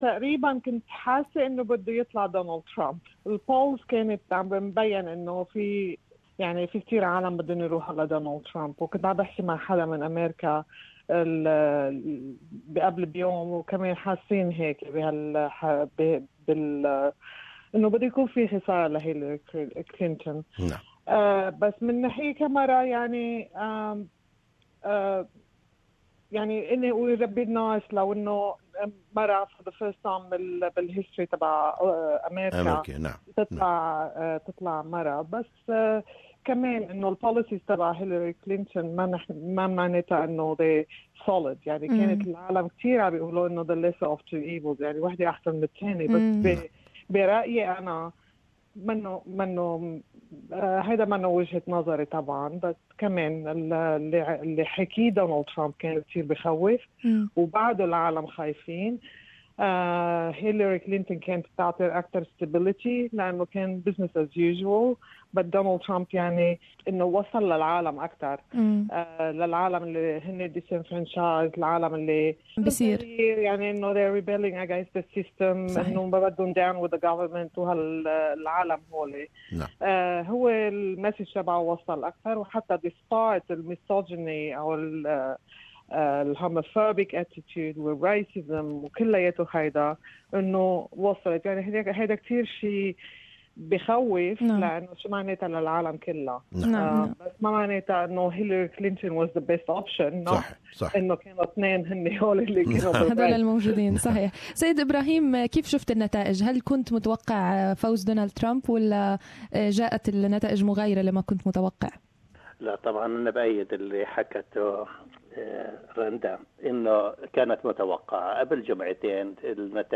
Is there going to be any significant change in American policy after winning Trump? Interview